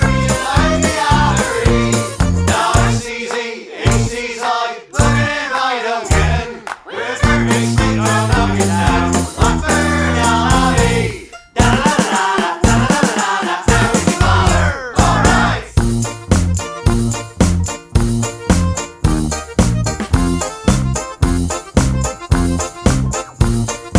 no Backing Vocals Comedy/Novelty 3:10 Buy £1.50